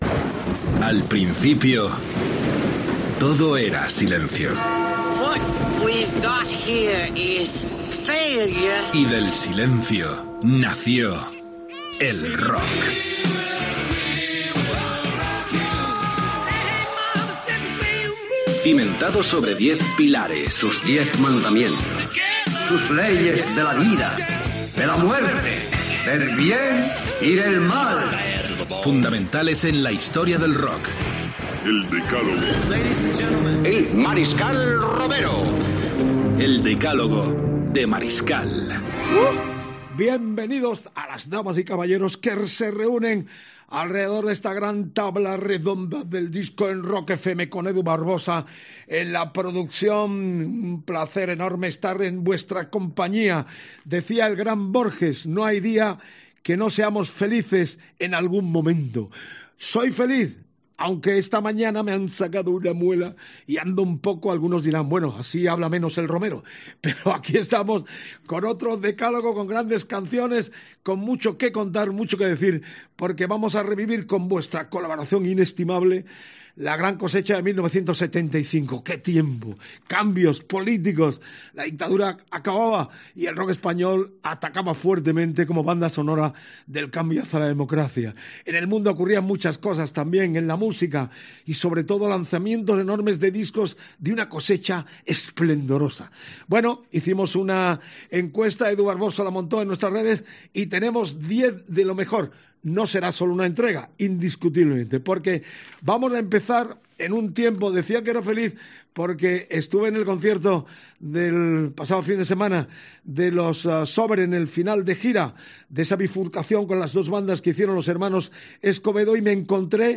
Careta del programa, presentació i espai dedicat als èxits de 1975
Musical
Romero, Vicente (Mariskal Romero)